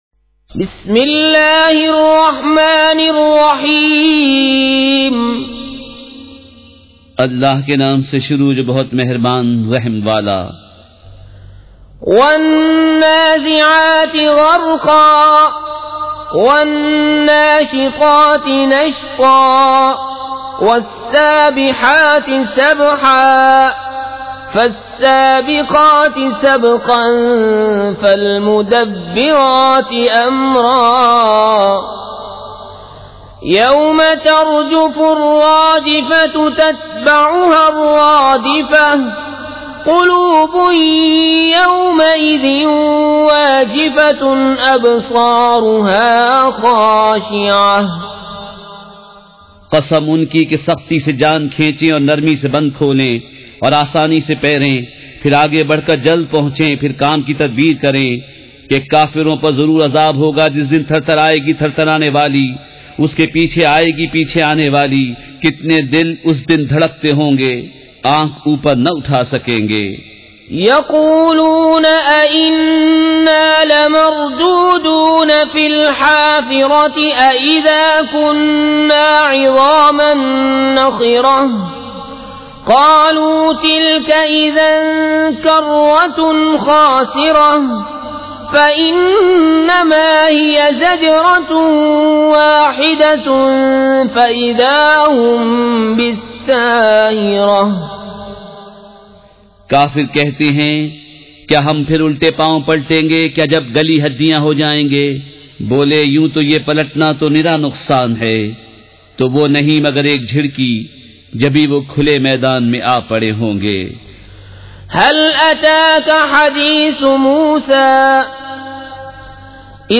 سورۃ النازعات مع ترجمہ کنزالایمان ZiaeTaiba Audio میڈیا کی معلومات نام سورۃ النازعات مع ترجمہ کنزالایمان موضوع تلاوت آواز دیگر زبان عربی کل نتائج 1842 قسم آڈیو ڈاؤن لوڈ MP 3 ڈاؤن لوڈ MP 4 متعلقہ تجویزوآراء